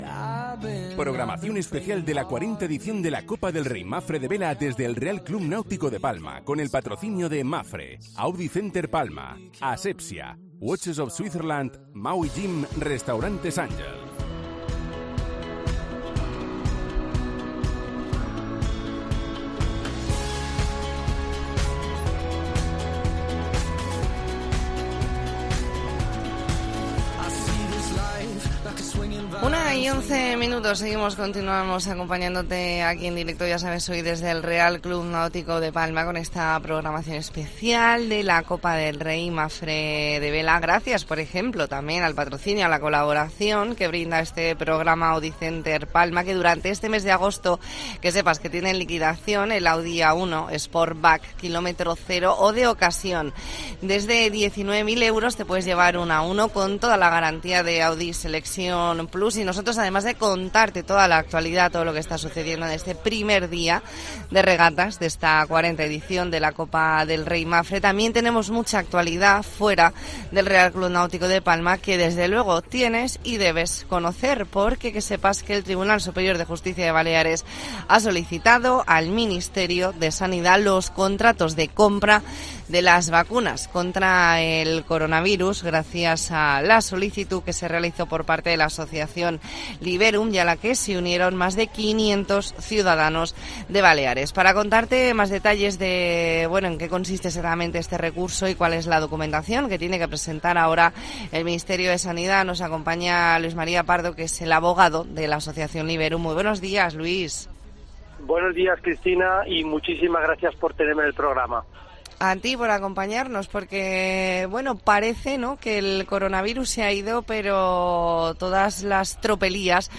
Entrevista en La Mañana en COPE Más Mallorca, lunes 1 de agosto de 2022.